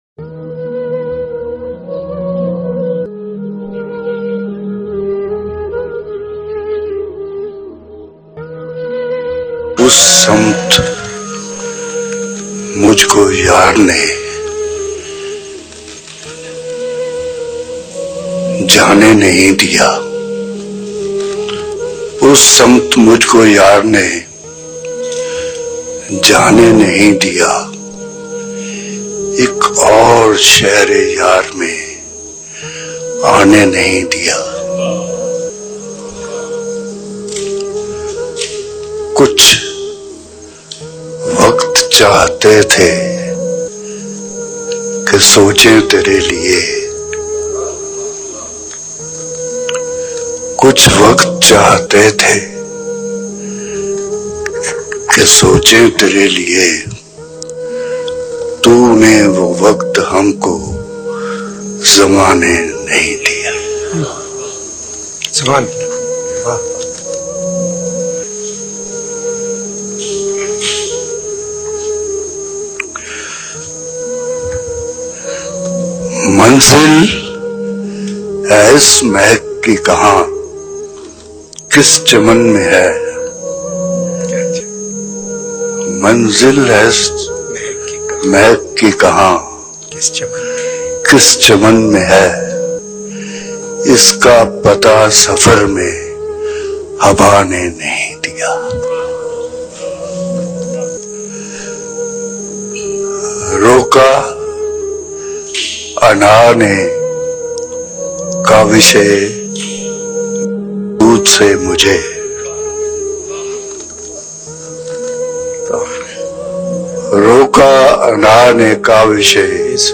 Munir niazi, Audio Books, Samt Mujh Ko Yar Ne Jane Nahin Diya